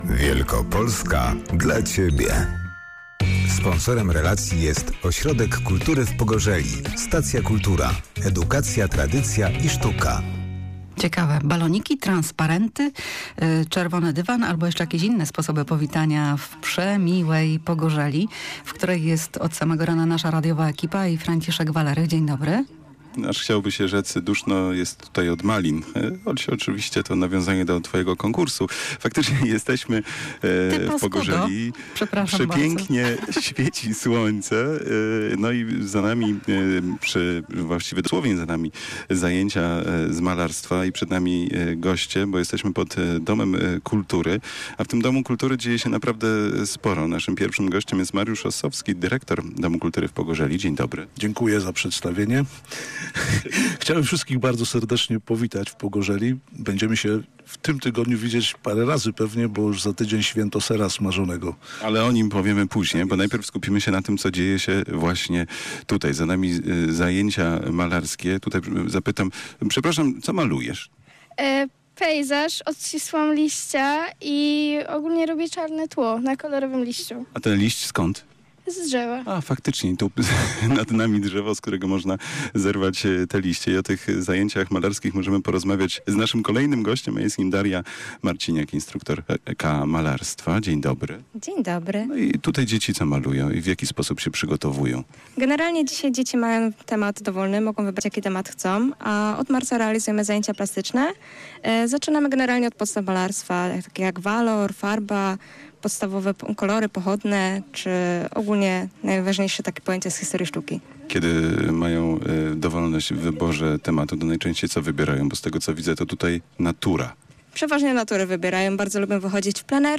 Pogorzela to kolejny przystanek na naszej wakacyjnej trasie!
Szczegóły jego przebudowy zdradził na antenie Piotr Curyk - burmistrz Pogorzeli.